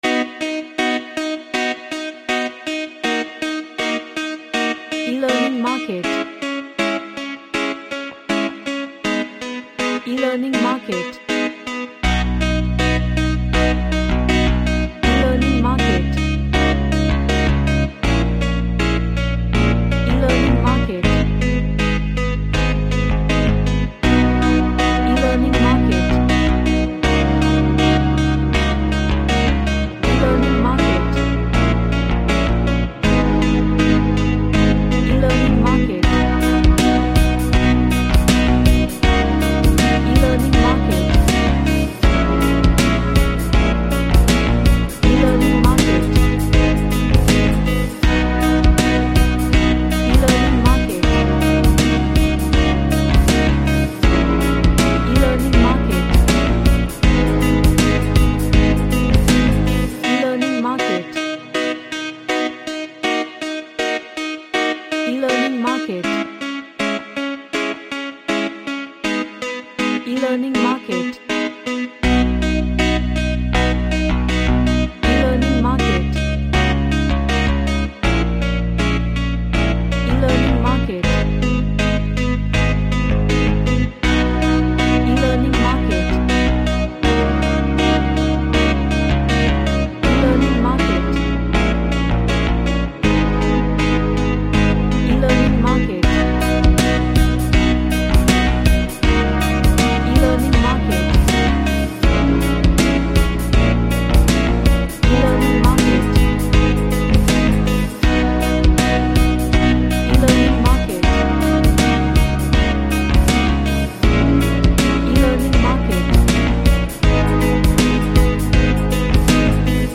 A reggae track with happy and up beat vibes.
Happy